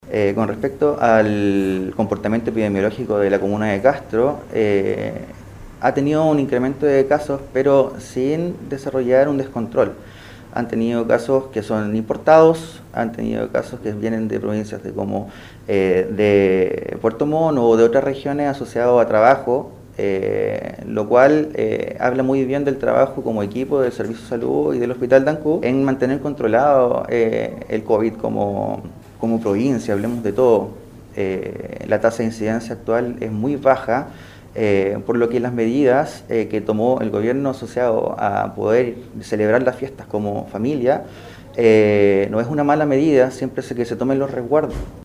al revisar en conferencia de prensa